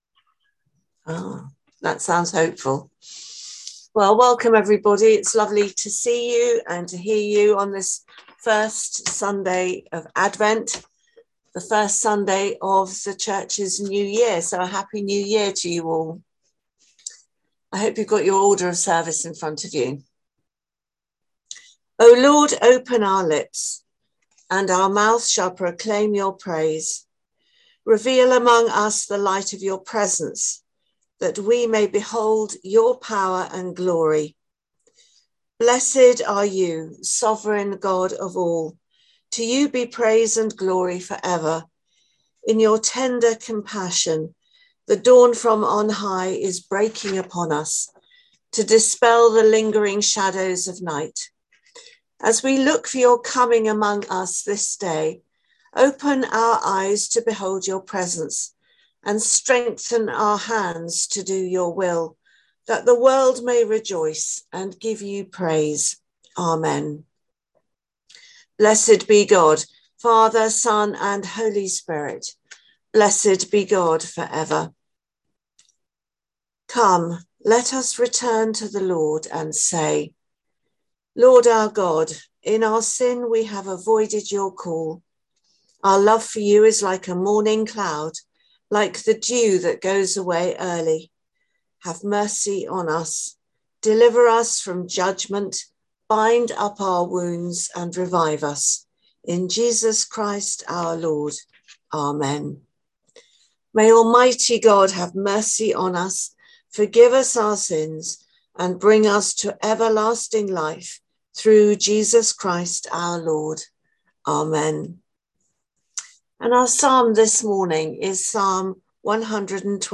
Morning Prayer - Sun, 18 Sep 2022
The audio from the Zoom / Conference Call Commemoration service for Queen Elizabeth II on 18/09/2022.